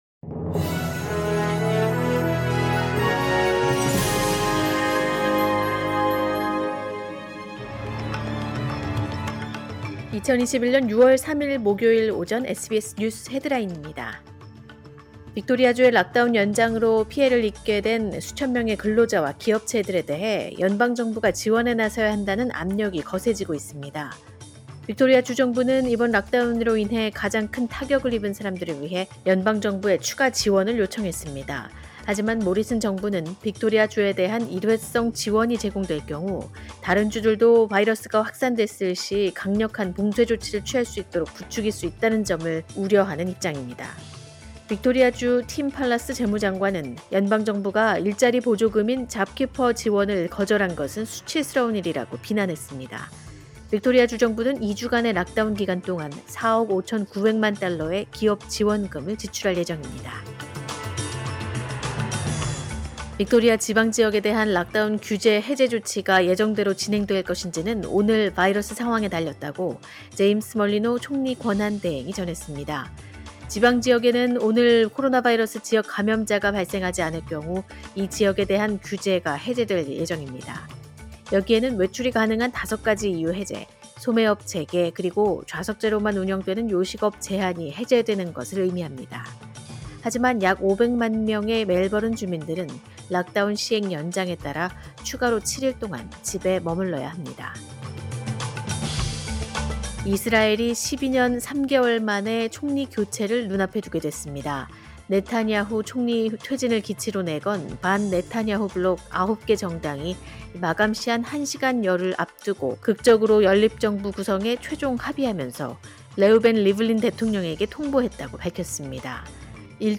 2021년 6월 3일 목요일 오전의 SBS 뉴스 헤드라인입니다.